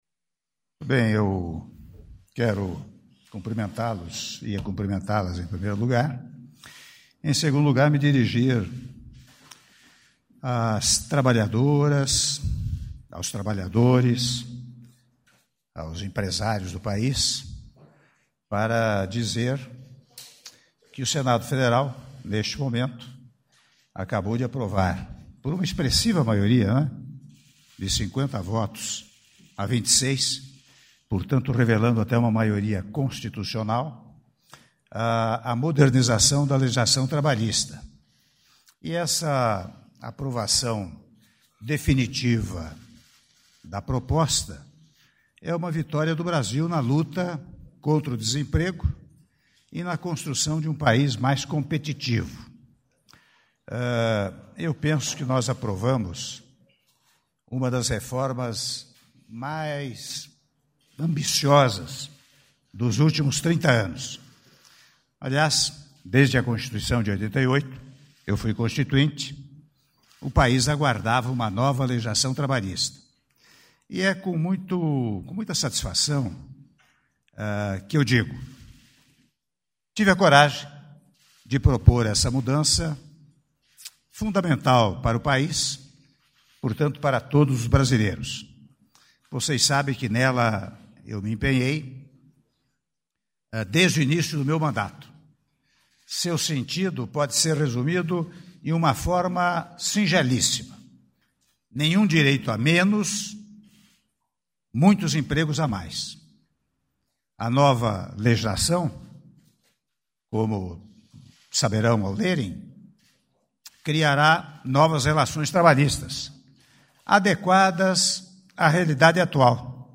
Declaração à imprensa do Presidente da República, Michel Temer - Palácio do Planalto (04min54s)